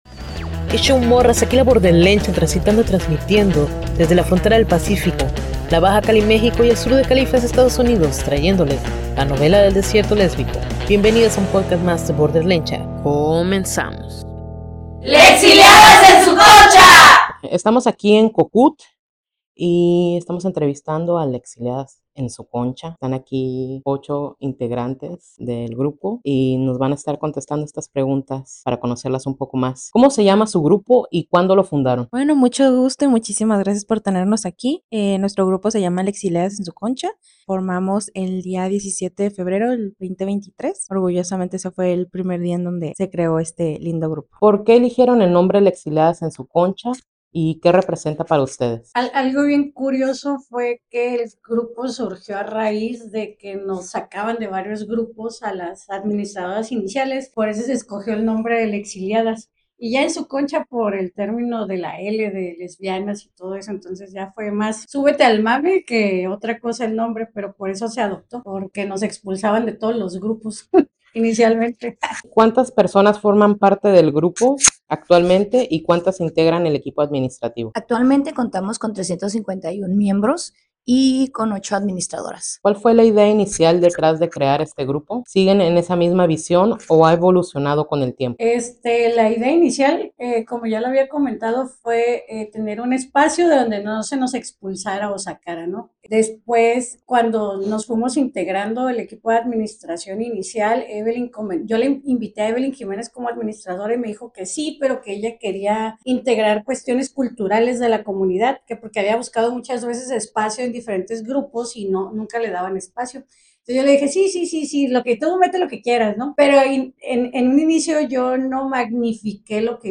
Lessxiliadas-en-su-concha-—-entrevista.mp3